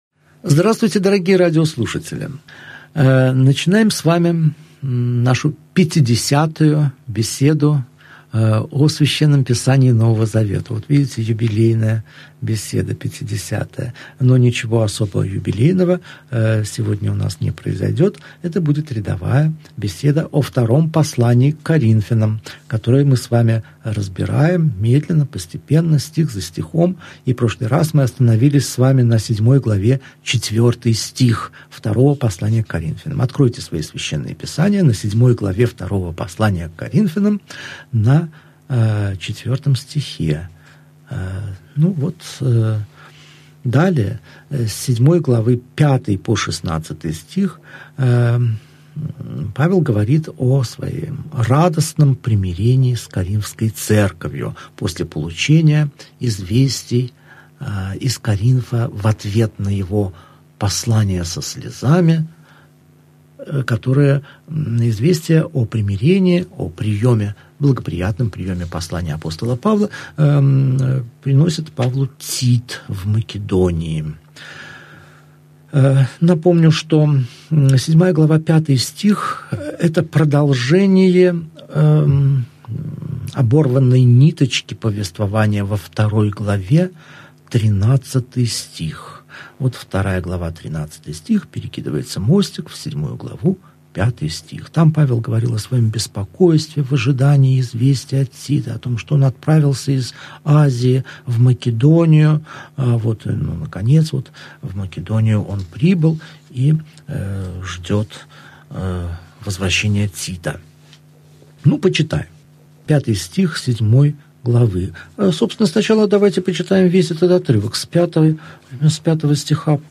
Аудиокнига Беседа 50. Второе послание к Коринфянам. Глава 7, стихи 5 – 16 | Библиотека аудиокниг